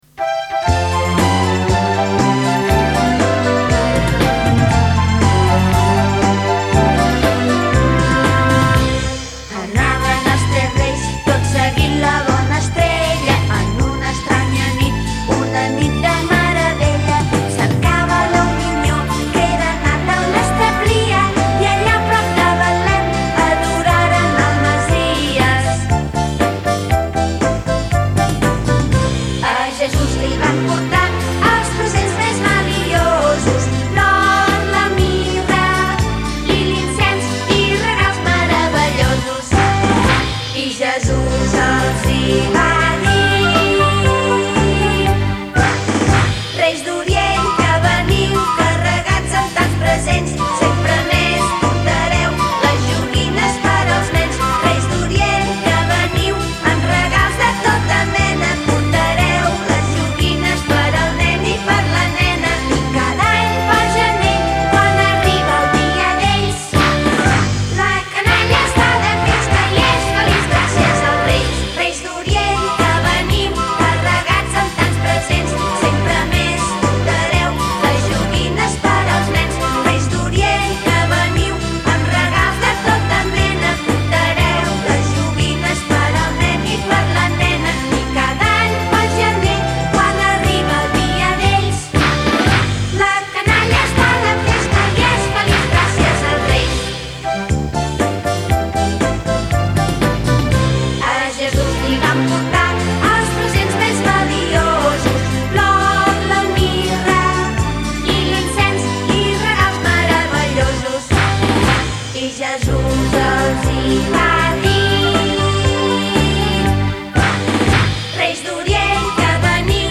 CANÇÓ DE NADAL